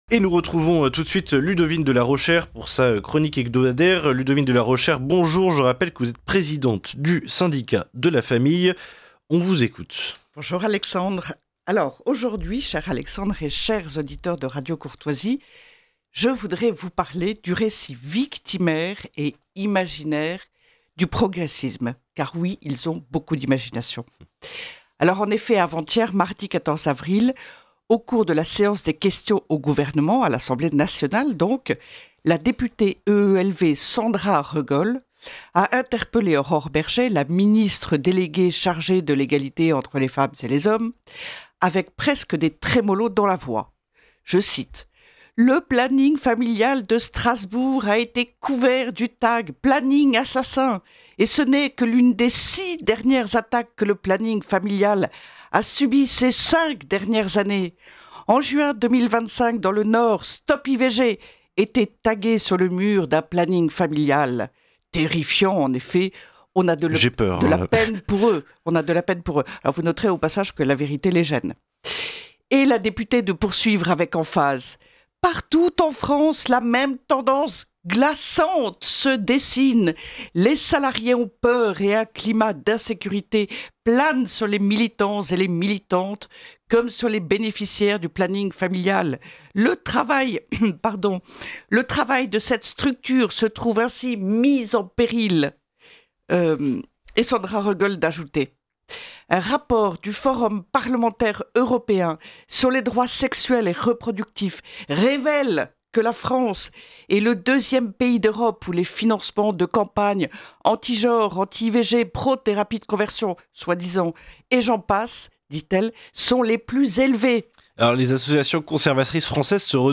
Retrouvez la chronique de Ludovine de La Rochère, diffusée chaque jeudi dans la matinale de Radio Courtoisie, pour connaître et comprendre l’essentiel de l’actualité qui concerne la famille.